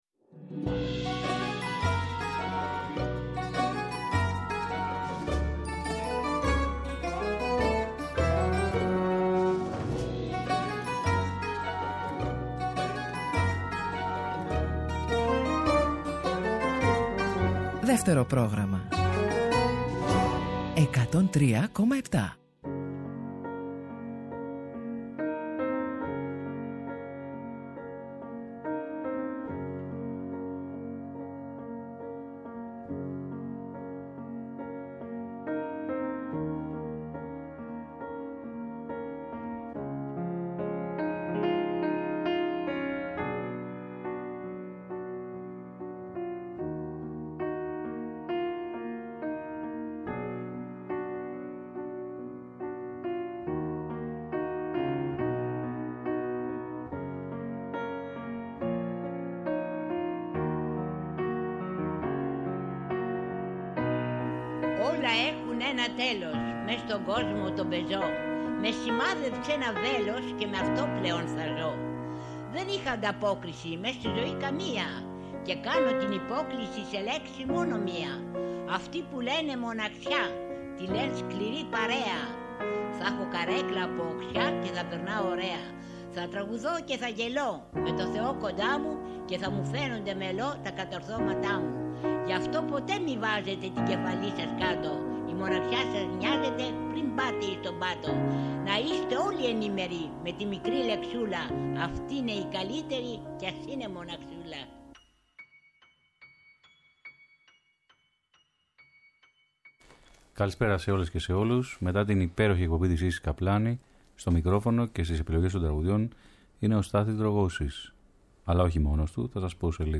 Υπάρχει κάπου κοντά τους μια κιθάρα… και μια έκπληξη!
Τραγούδια τρυφερά πολύ στο Δεύτερο Πρόγραμμα.
Συνεντεύξεις